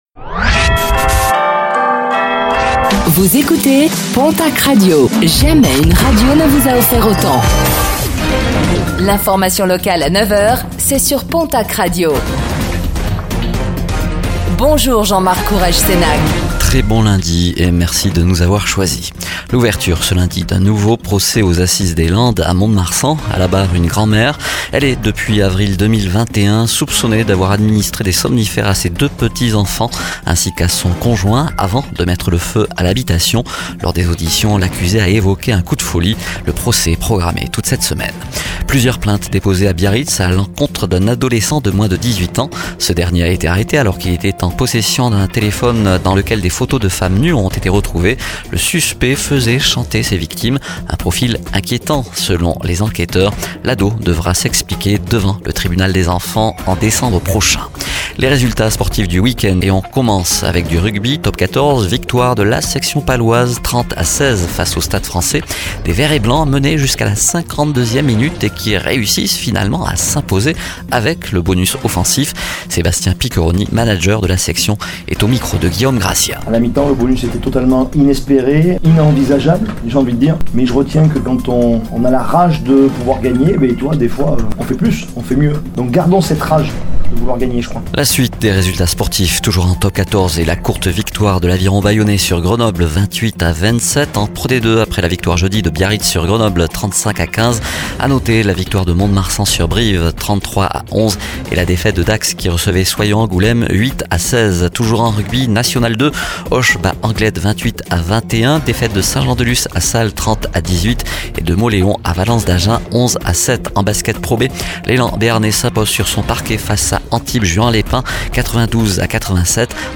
Infos | Lundi 30 septembre 2024